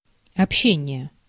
Ääntäminen
IPA: [kɔ.my.ni.ka.sjɔ̃]